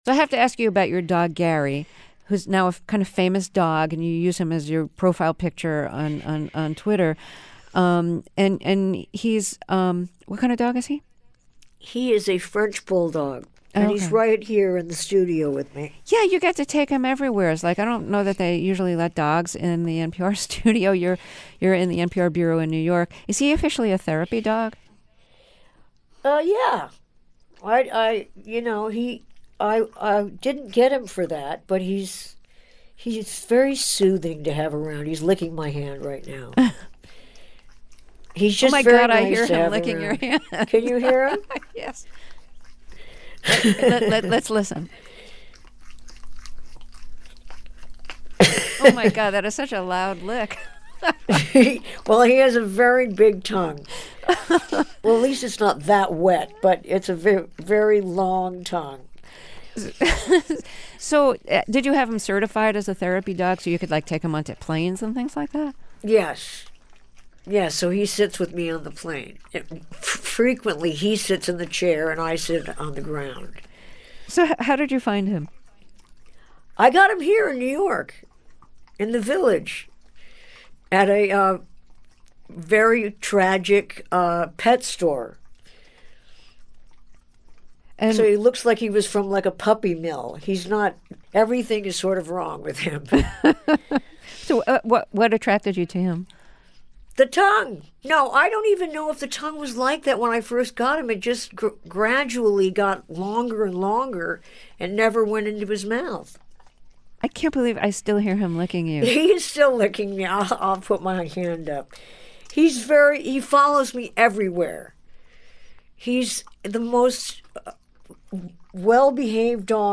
Turns out Gary was right there in the studio.